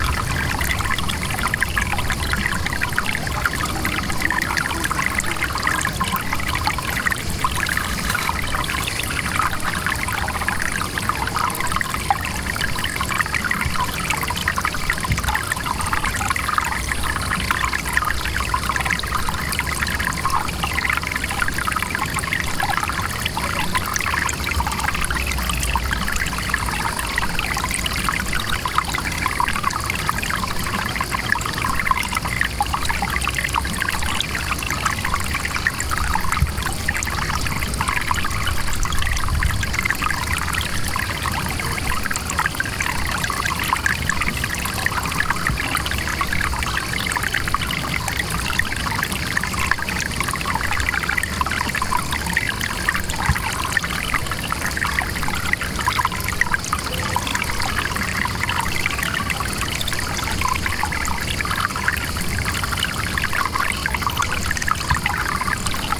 Pendulo recording the water of the river Sinello
Al termine della residenza Pêndulo ha presentato un’istallazione sonora istallata nella ciminiera camino di casa GAP di cui qui potete ascoltare l’
Una nota buffa è che il sindaco di Guilmi, non informato dell’istallazione, ci ha telefonato allarmato, chiedendoci di andare a controllare i rubinetti di casa, perché sembrava che si stesse allagando!